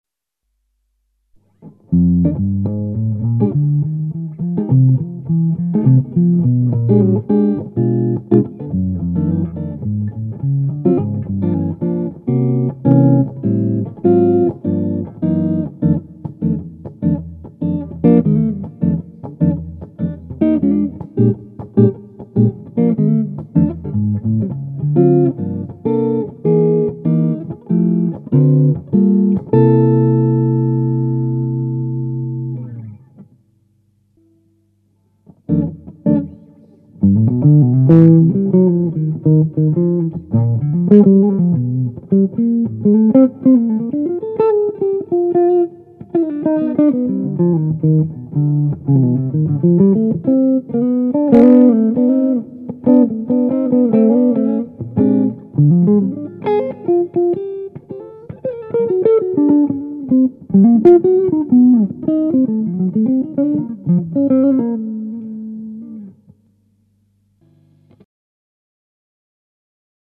Voici une serie d'enregistrements qui ont été fait sur le Ten, l'ampli 10 watt de Pasqualiamps.
Le baffle utilisé est un "closed back" ave 2 HP Celestion G12-H. Les 3 premières plages ne contiennent aucun effet.
Plage 1 "Jazz"
Réglages Tactile Custom: Micro manche double Tone 90 % - Volume 100 %
Réglage Pasqualiamps Ten: Tone 0 - Volume 1
Pas d'effets.